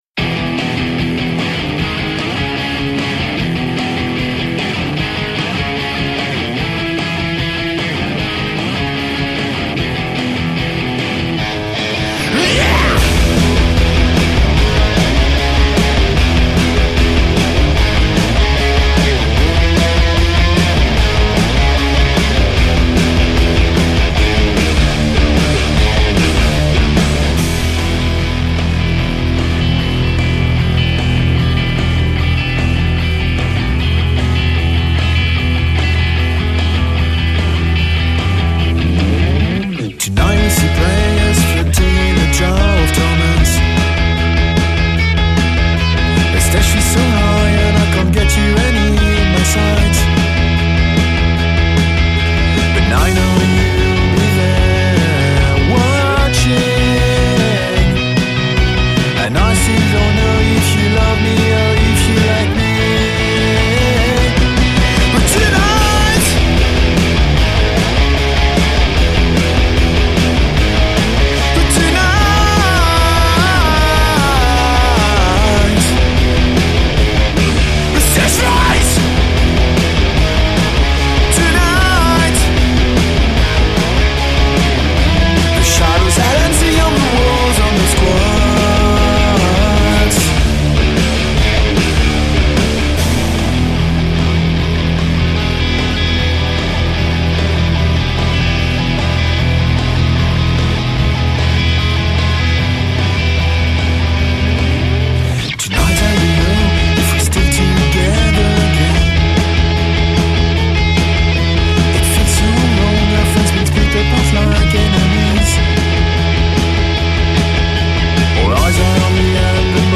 loud fuzz rock band with surfpunk and heavy pop undertones